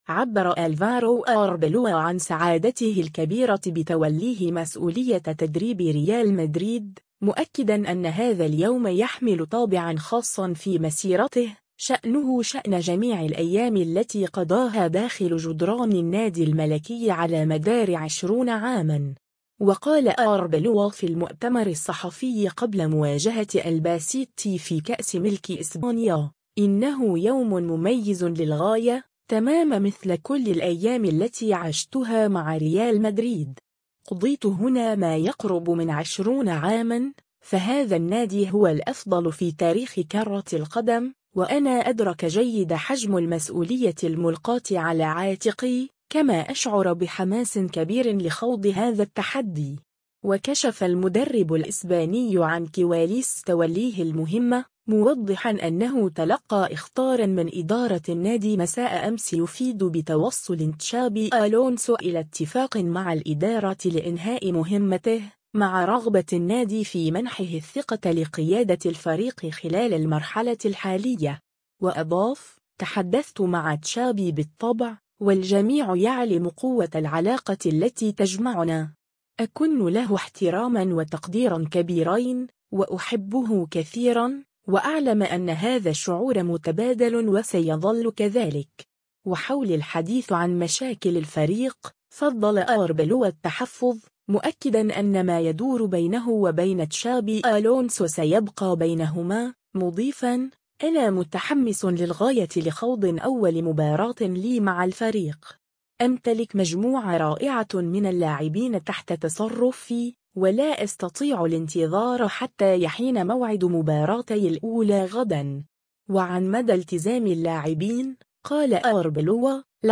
و قال أربيلوا في المؤتمر الصحفي قبل مواجهة ألباسيتي في كأس ملك إسبانيا : “إنه يوم مميز للغاية، تمامًا مثل كل الأيام التي عشتها مع ريال مدريد. قضيت هنا ما يقرب من 20 عامًا، فهذا النادي هو الأفضل في تاريخ كرة القدم، و أنا أدرك جيدًا حجم المسؤولية الملقاة على عاتقي، كما أشعر بحماس كبير لخوض هذا التحدي”.